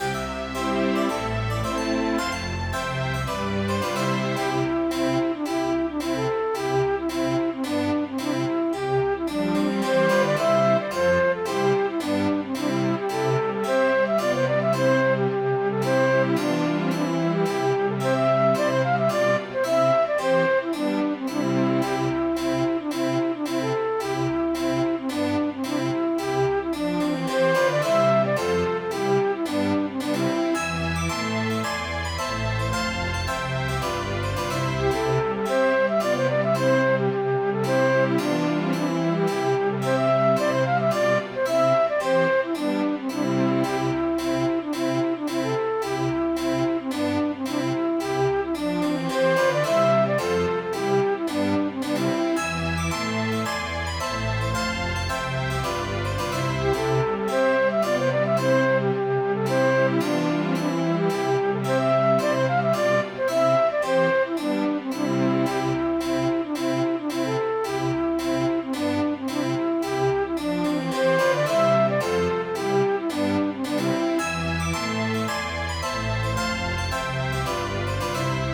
roywife.mid.ogg